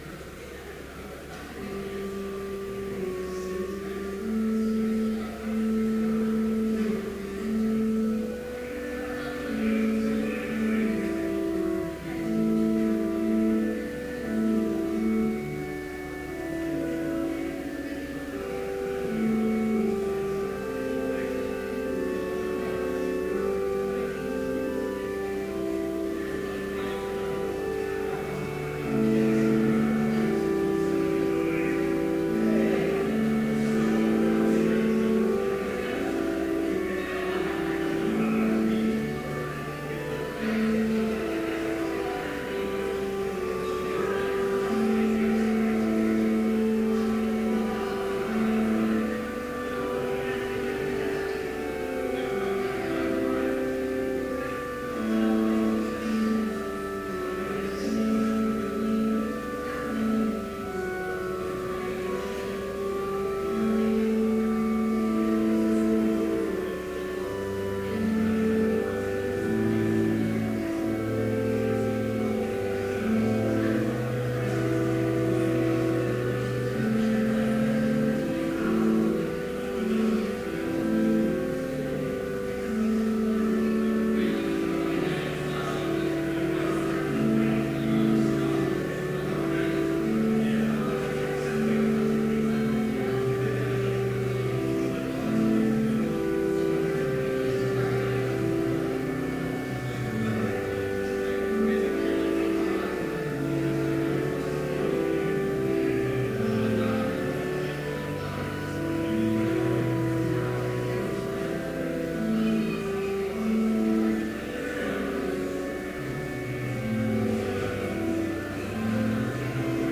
Complete service audio for Chapel - February 12, 2014